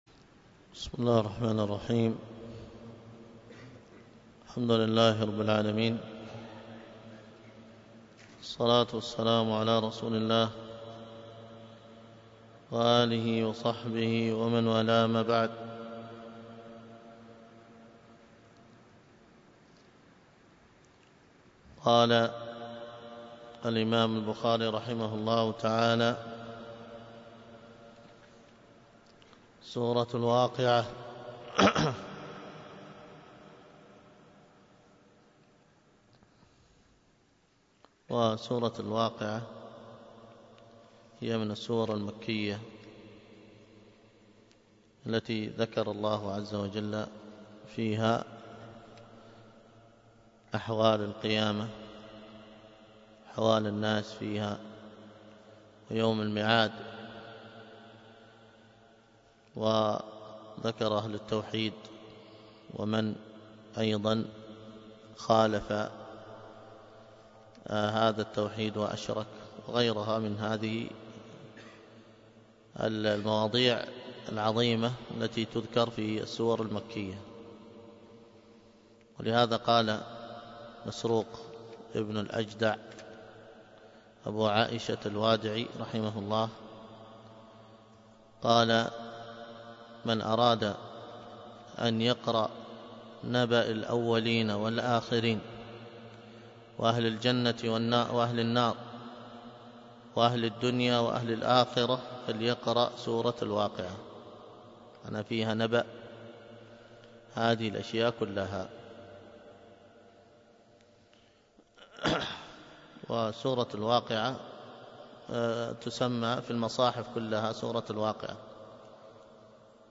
المحاضرة بعنوان من صفات الأسرة المسلمة، وكانت بمسجد الخير بالخزان - الشحر